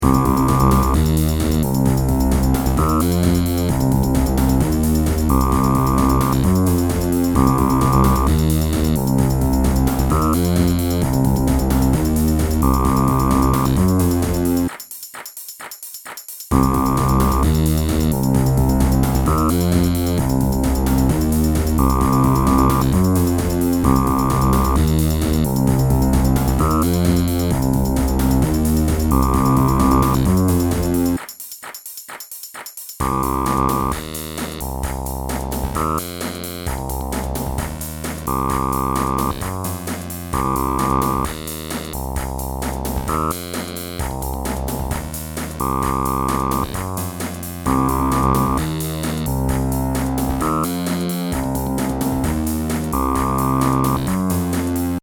Bucle de Electro Rock
Música electrónica
melodía
rock
sintetizador